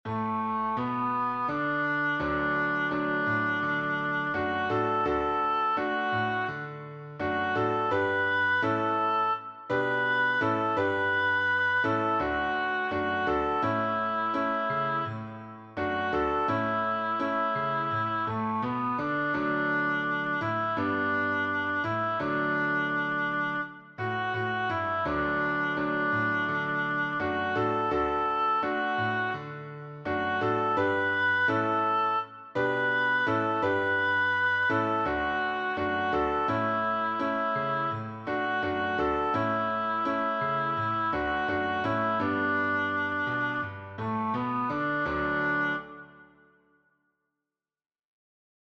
The style is reminiscent of old-fashioned Baptist hymns.